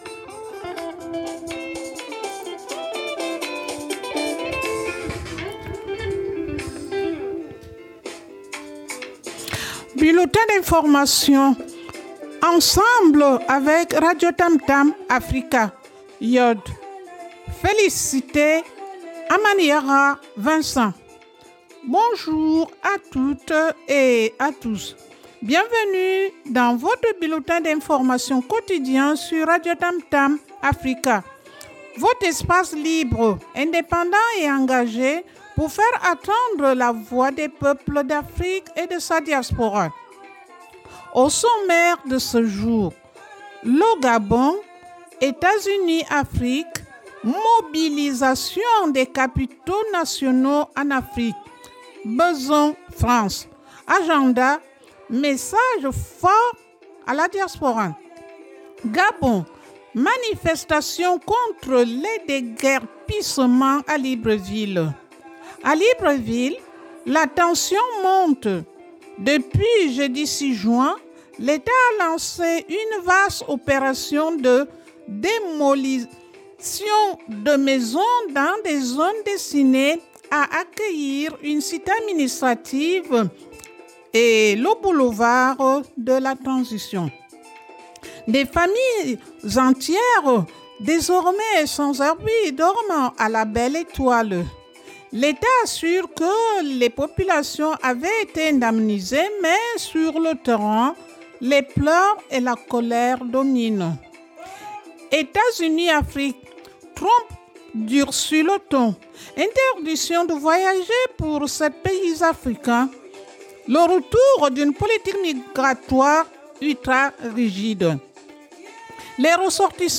Radio TAMTAM AFRICA BULLETIN D’INFORMATION BULLETIN D'INFORMATION 08 juin 2025